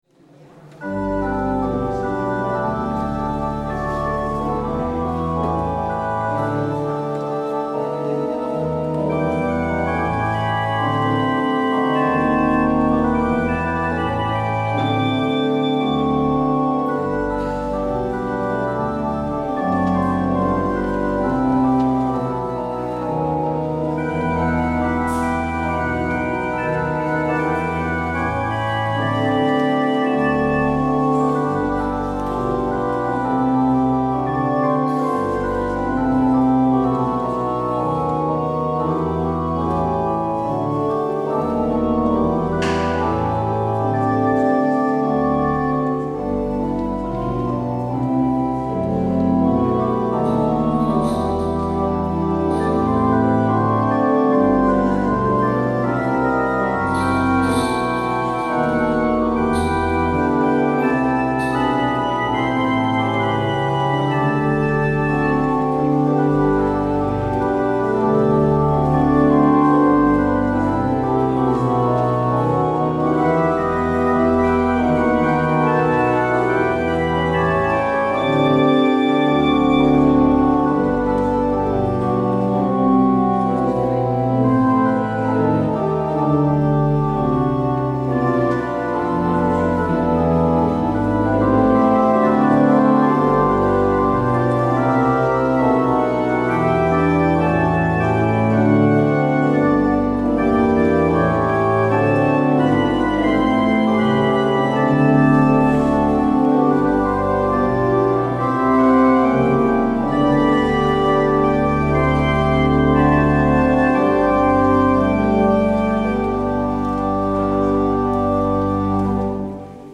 Vandaag is de voorganger Prof. Antoine Bodar.